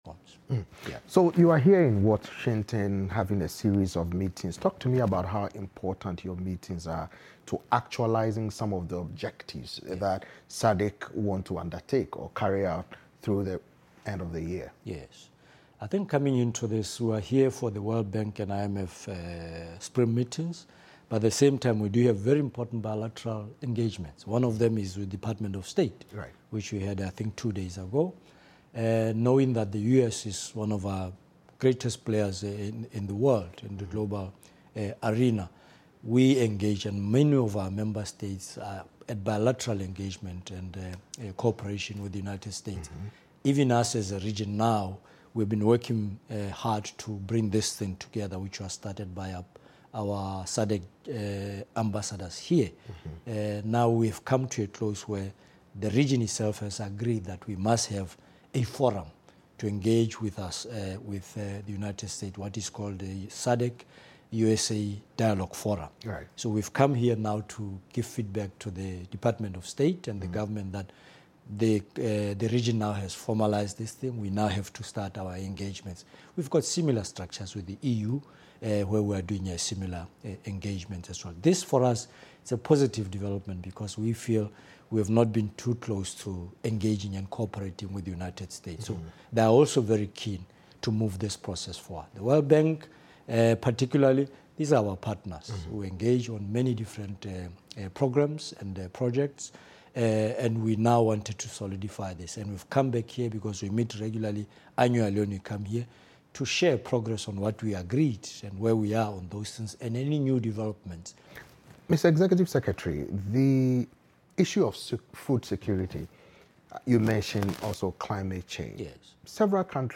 This is the conclusion of the conversation.